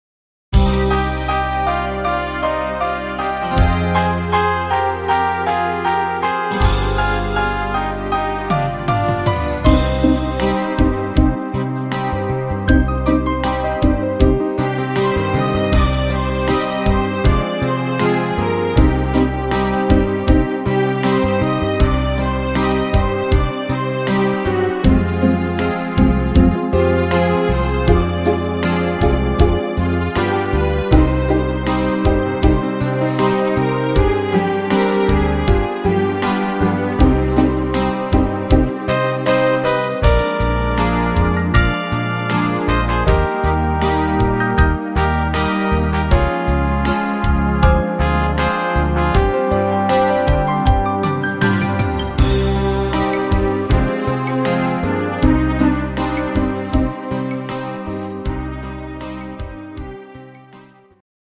(instr. Klarinette)